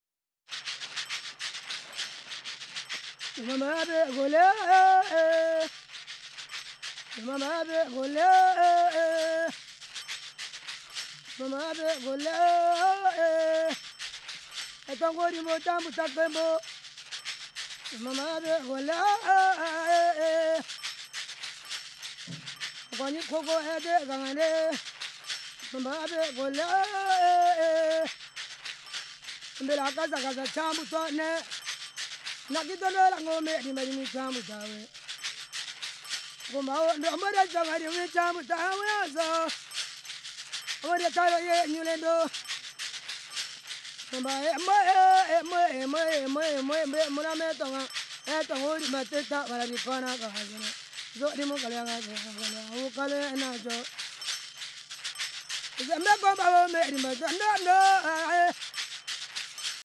Grelot et sonnaille
Lorsqu’on secoue l’instrument, la bille qui frappe le récipient fait tinter celui-ci.
Ce type d’instrument apparaît dans d’enregistrements de nos archives sonores, réalisés parmi les peuples congolais suivants ; il est connu sous les noms vernaculaires suivants: